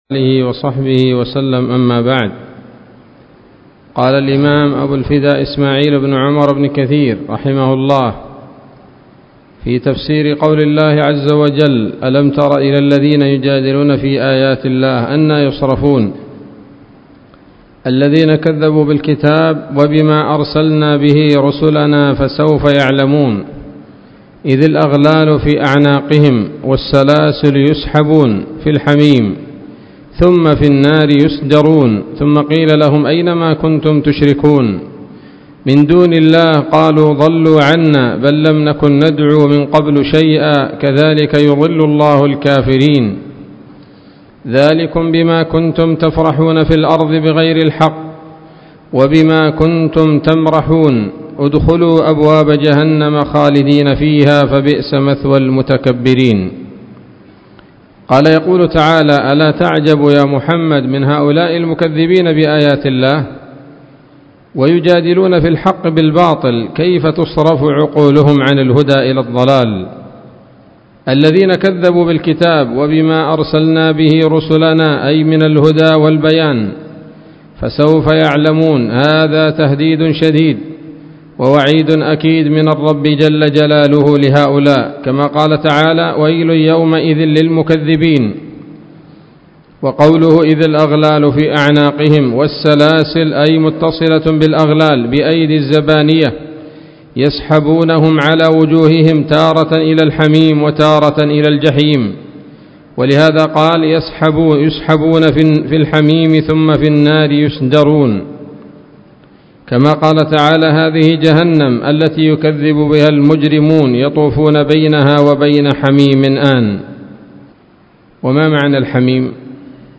الدرس السادس عشر وهو الأخير من سورة غافر من تفسير ابن كثير رحمه الله تعالى